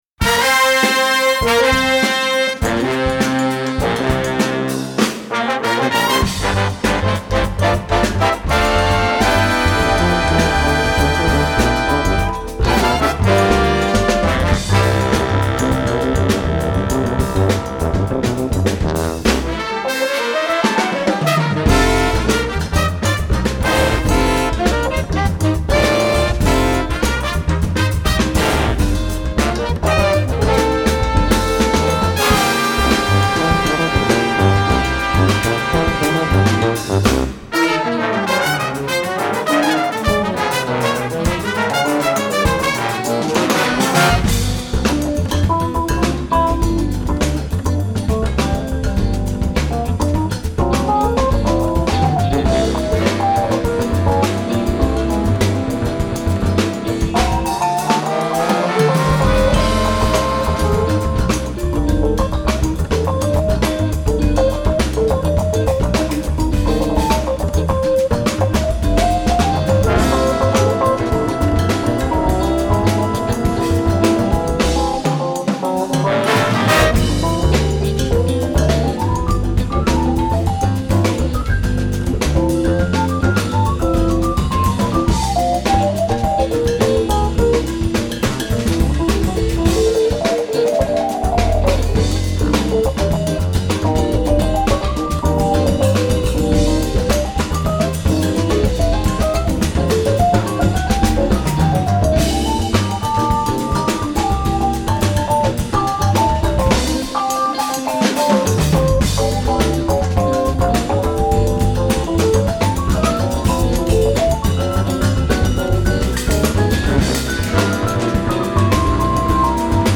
Genre: Jazz / Classical / Creative Music
trumpet